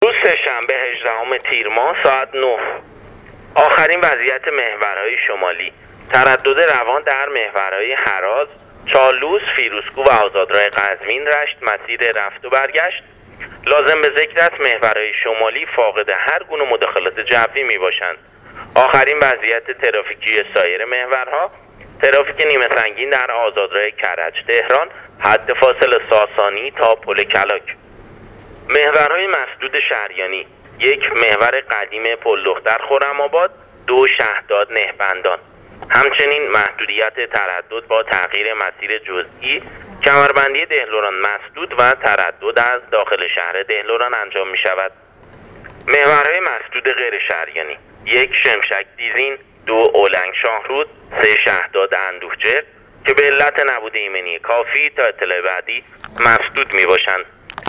گزارش رادیو اینترنتی وزارت راه و شهرسازی از آخرین وضعیت‌ ترافیکی راه‌های کشور تا ساعت ۹ هجدهم تیرماه/ تردد عادی و روان در همه محورهای شمالی کشور / ترافیک نیمه سنگین در آزادراه کرج - تهران